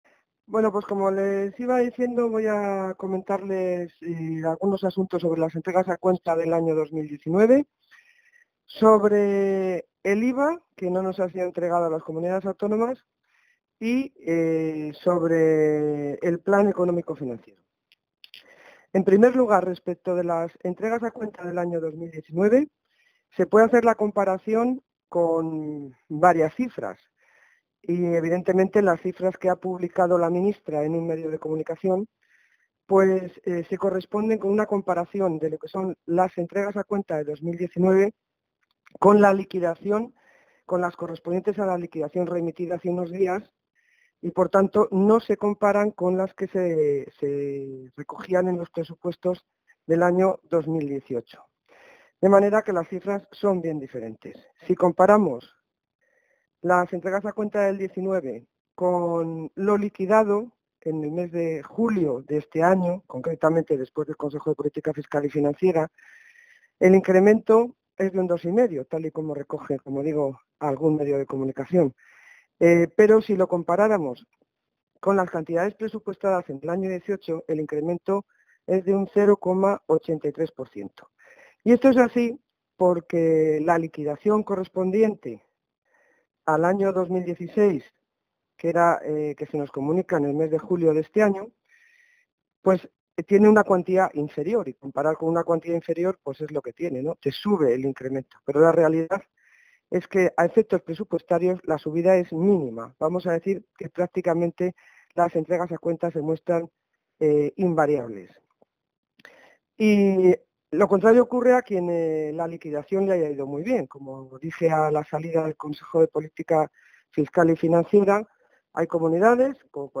Declaraciones de la consejera de Economía y Hacienda sobre las entregas a cuenta para 2019 y el Plan Económico-Financiero de Castilla y León 2018-2019 | Comunicación | Junta de Castilla y León
Consejera de Economía y Hacienda.